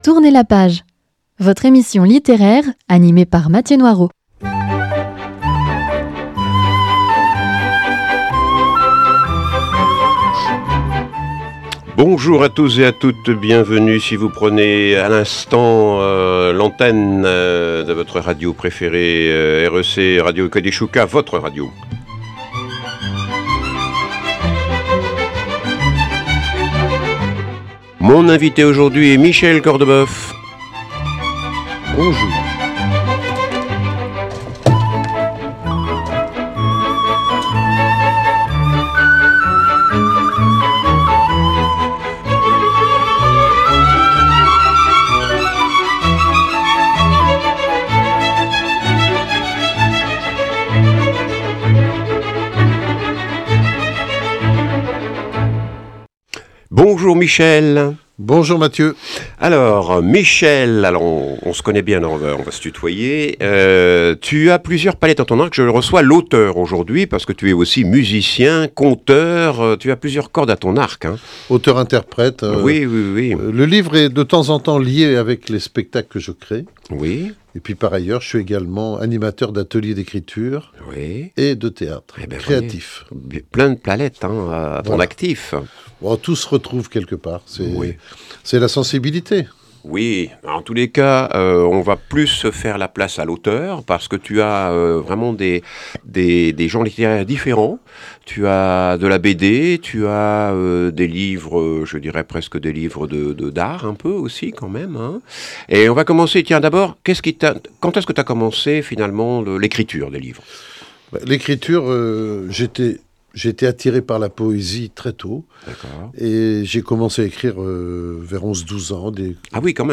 Émission littéraire avec un·e invité·e : auteur ou autrice qui nous parle de son métier, de ses ouvrages ou de son dernier livre édité.